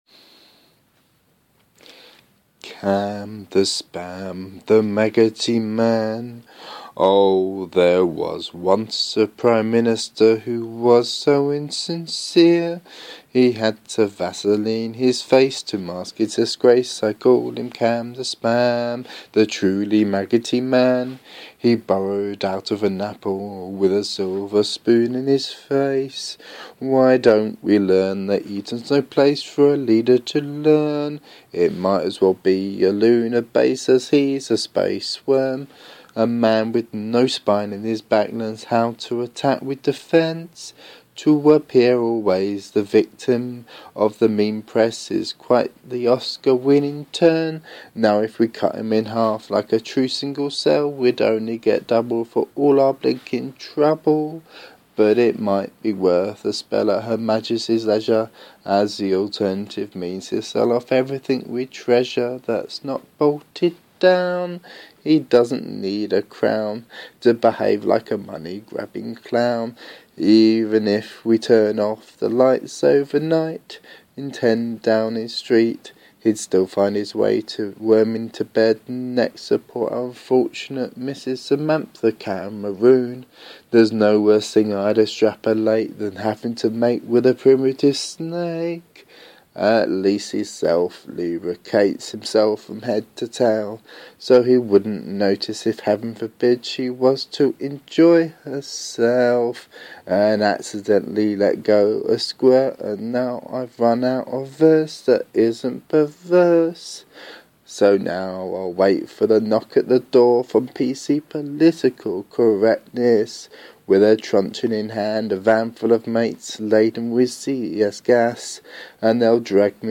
Song/Shanty